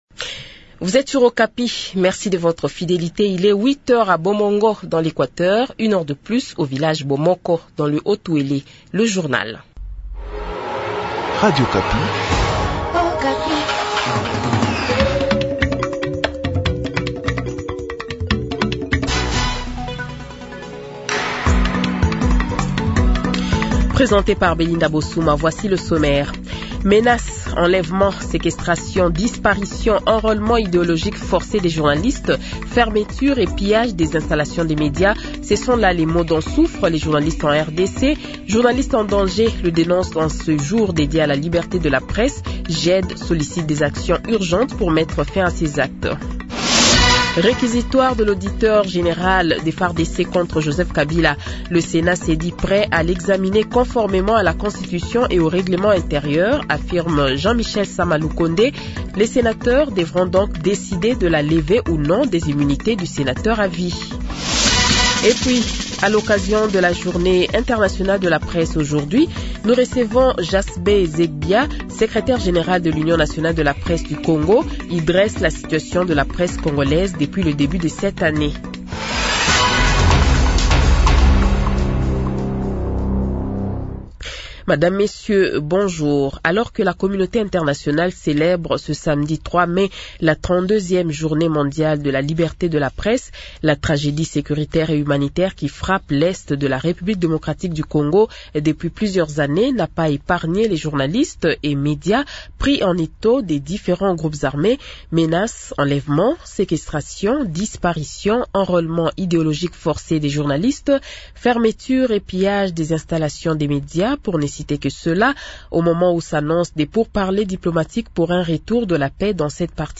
Le Journal de 7h, 03 Mai 2025 :